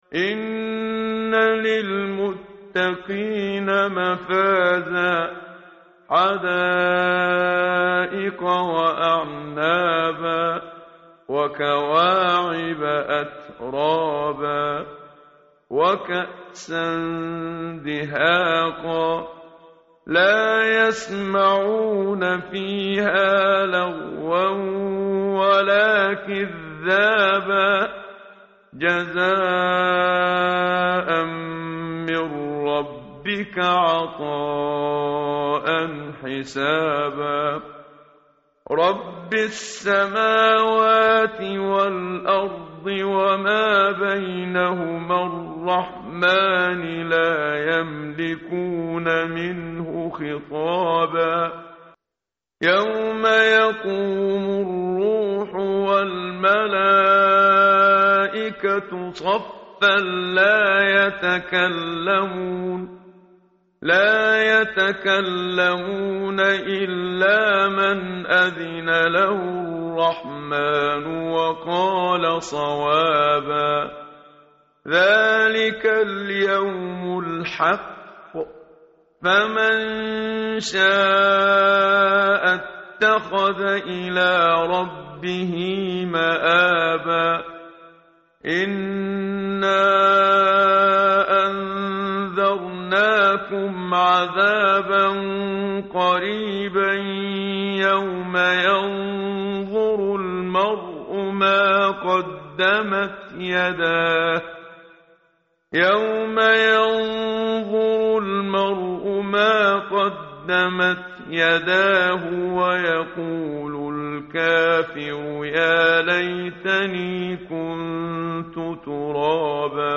tartil_menshavi_page_583.mp3